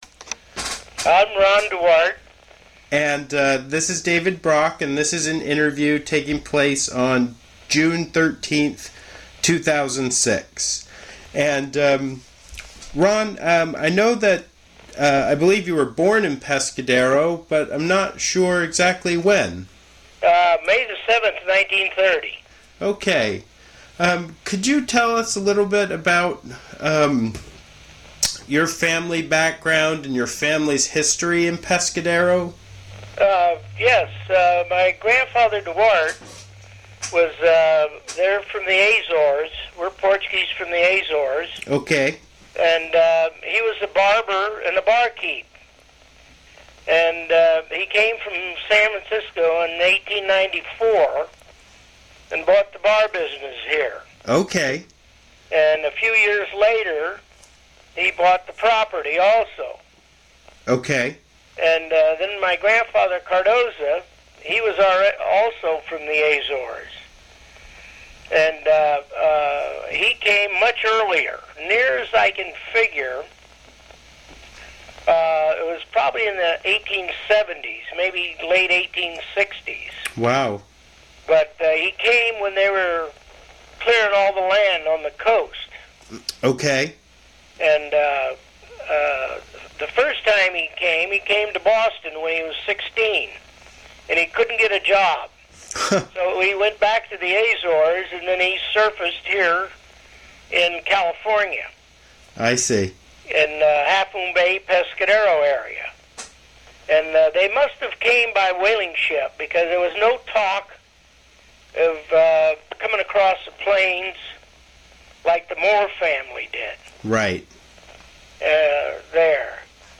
Oral history interview
Place of interview California--Pescadero
Genre Oral histories